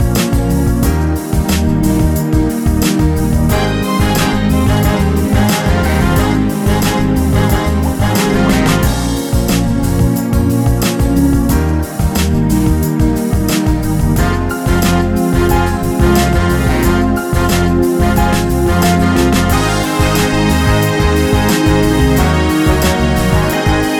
One Semitone Down Pop (1990s) 3:41 Buy £1.50